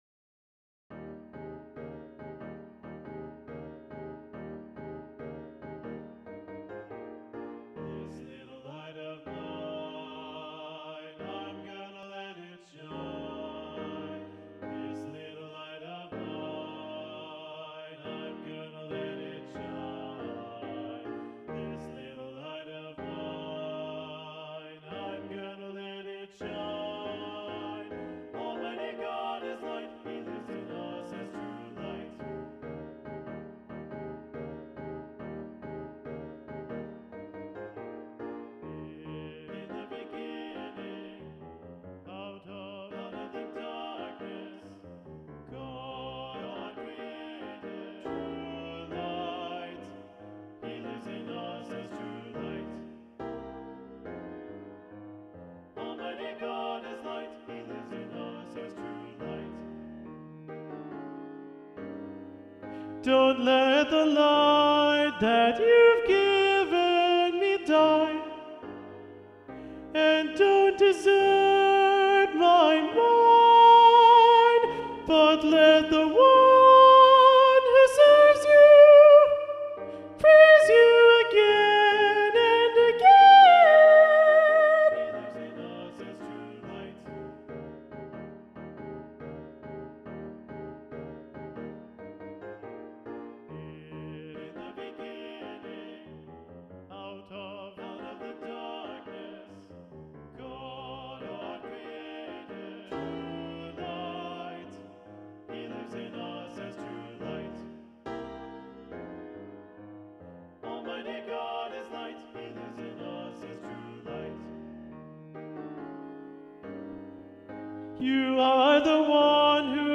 True Light - Solo Predominant